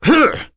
Roi-Vox_Attack2.wav